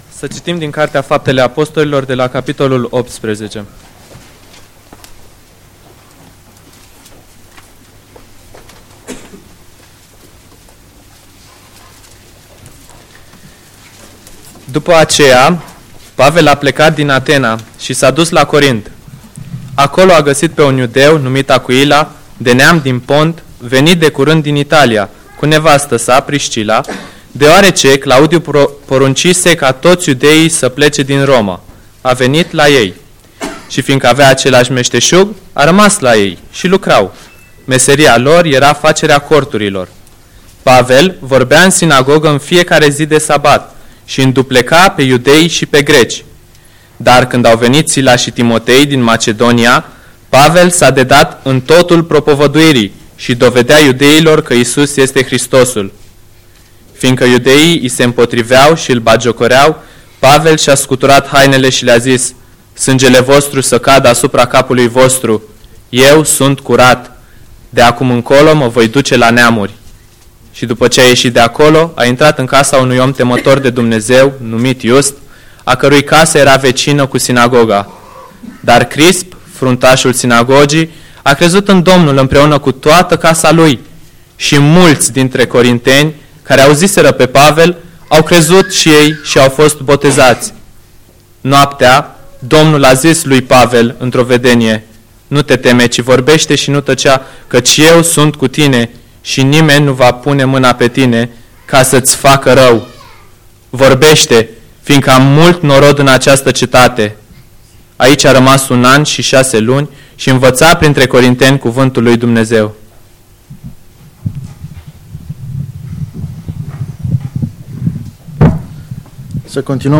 Tineret, seara Predică, Faptele apostolilor 18:1-22